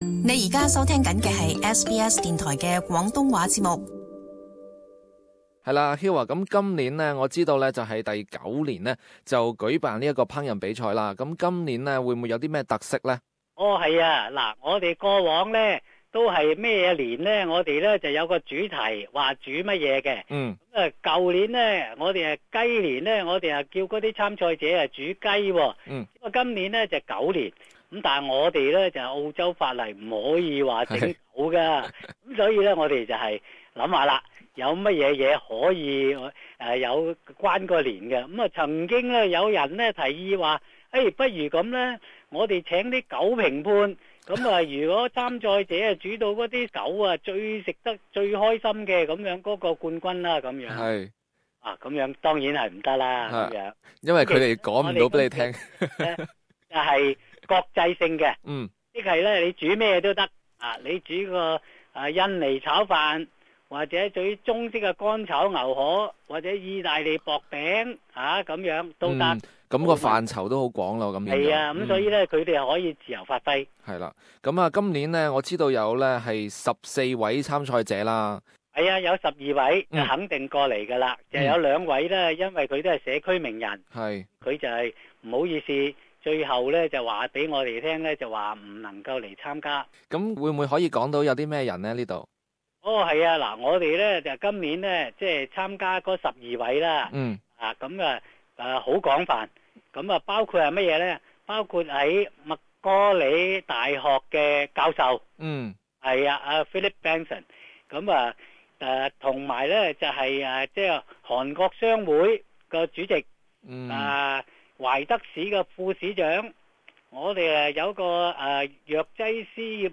Community Interview: Eastwood 9th Annual LNY Cooking Competition